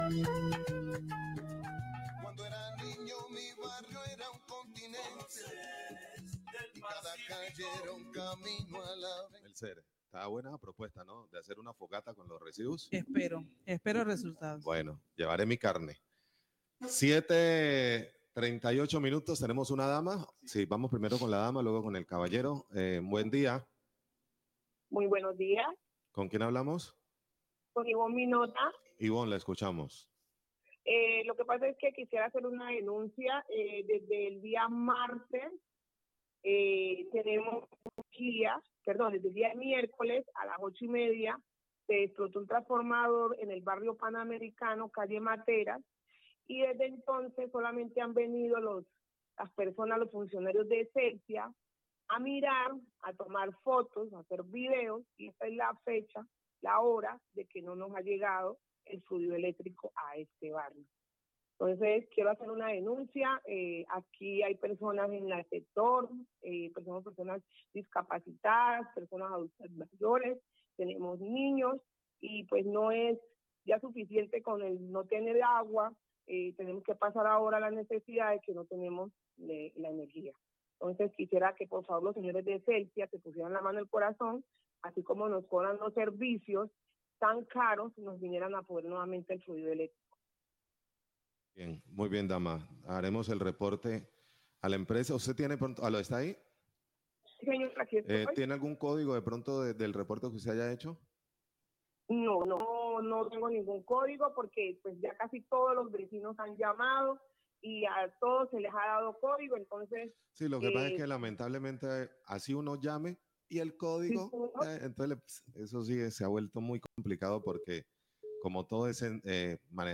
Oyente se queja por falta del servicio de energía en el sector desde hace tres días,738am
Radio